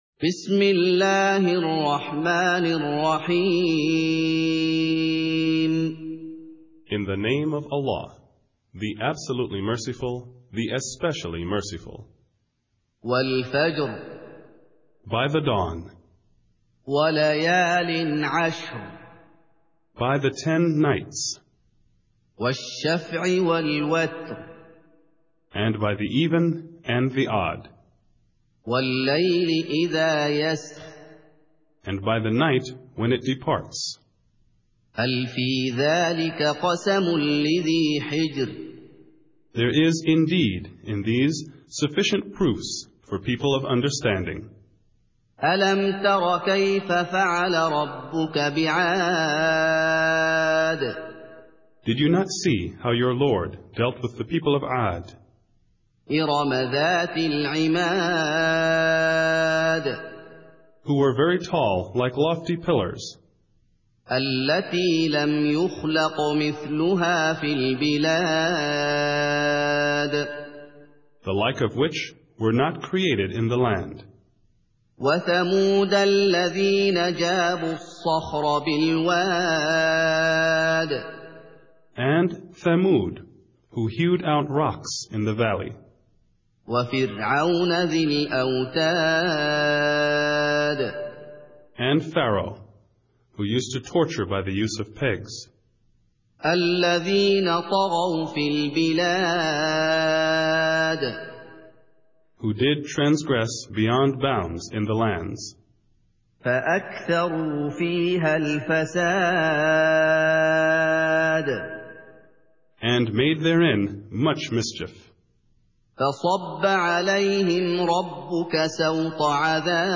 اللغة الإنجليزية التلاوة بصوت الشيخ محمد أيوب